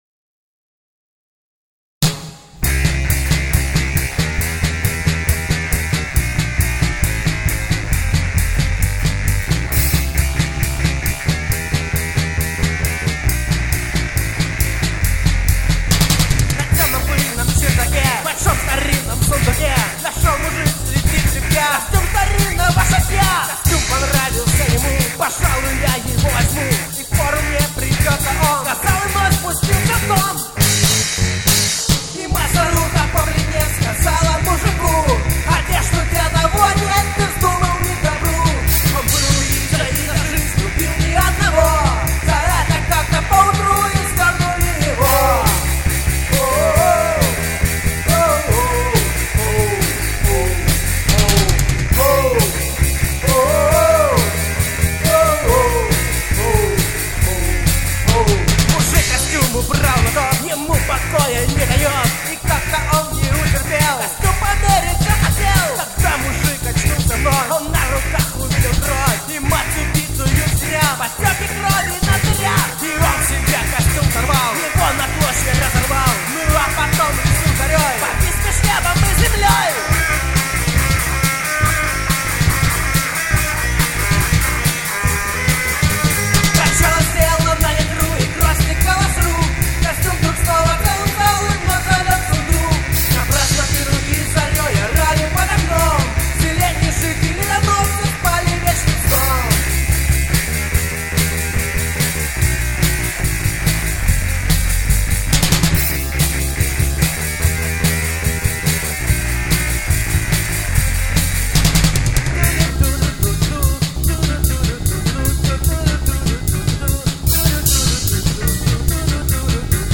Гитары, бас, вокал
Барабаны